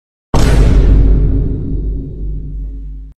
Play PUM IMPACTO - SoundBoardGuy
Play, download and share PUM IMPACTO original sound button!!!!
pum-impacto.mp3